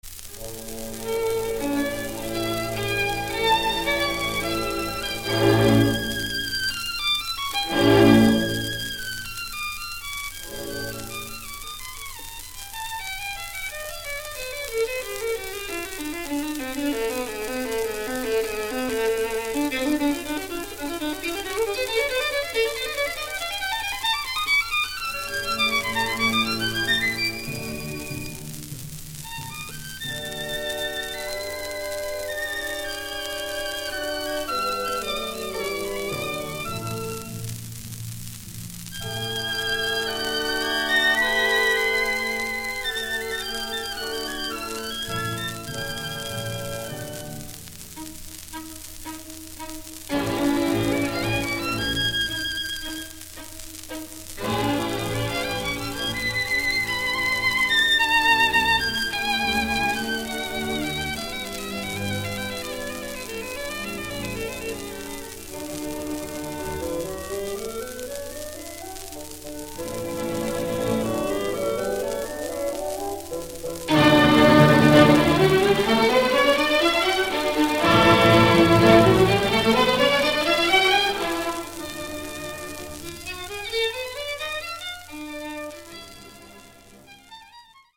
(1936年6月16,17,22日ロンドン, アビー・ロード第1スタジオ録音)
EMI録音の完成期の見事な音はＳＰ時代の再生法では再生不可能だった。